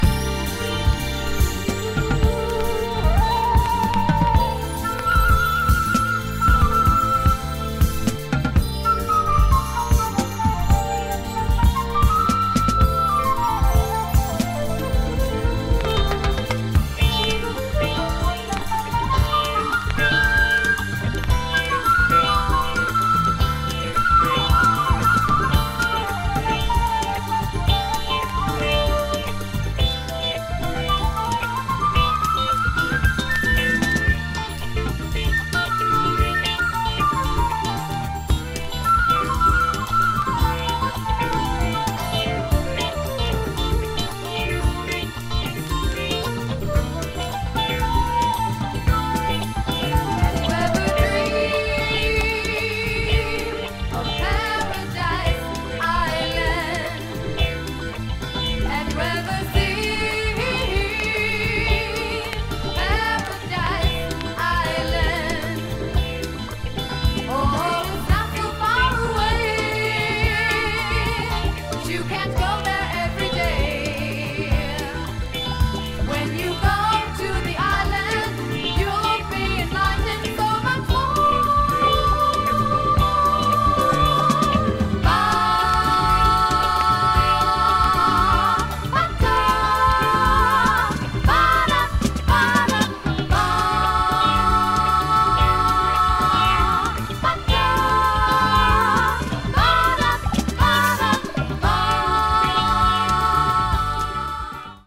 Cut loud.